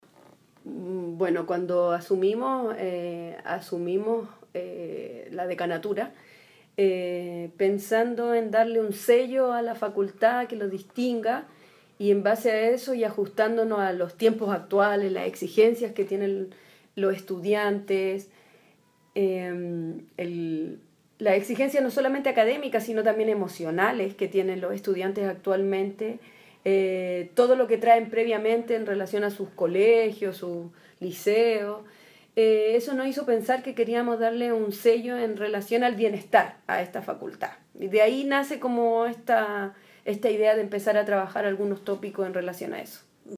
Cuña